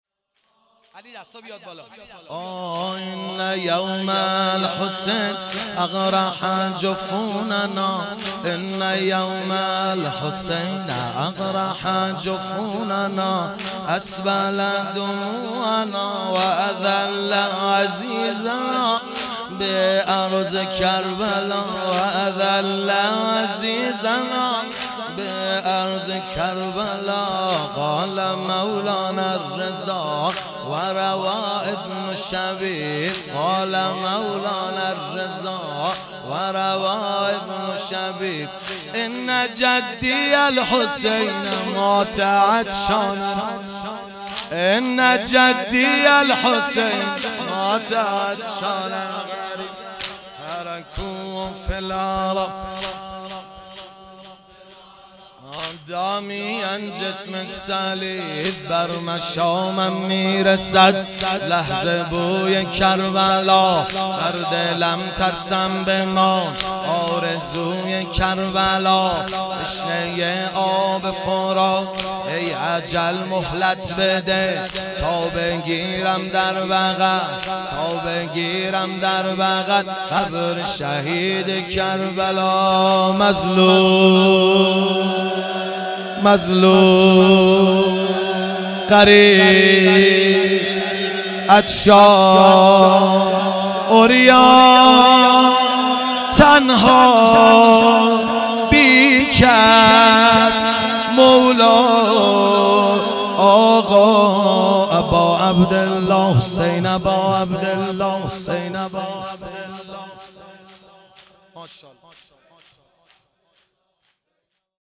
شب اول محرم 97 - واحد - ان یوم الحسین